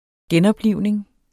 Udtale [ ˈgεnʌbˌliwˀneŋ ]